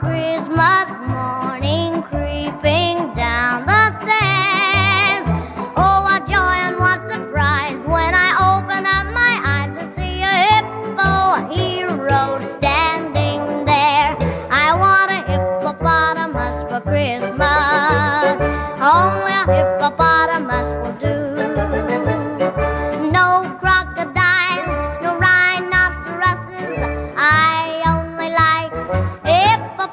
Genre: Vocal